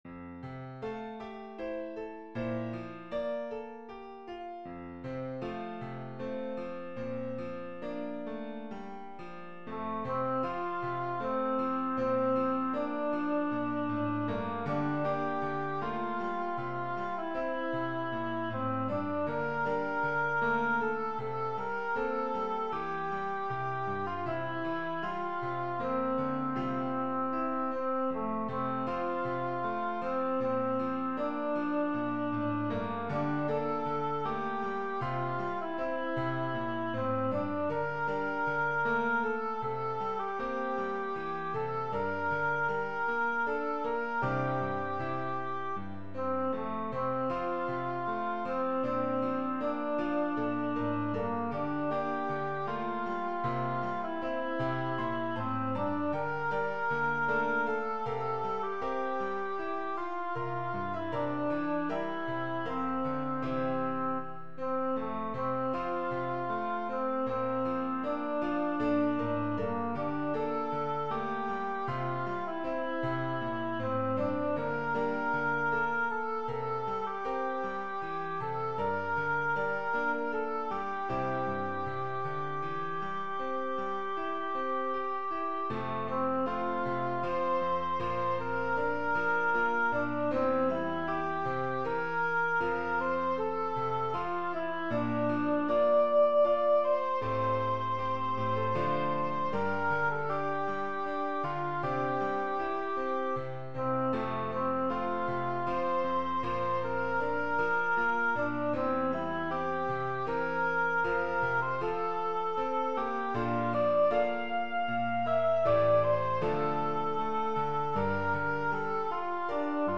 (Soprano Solo with Violin)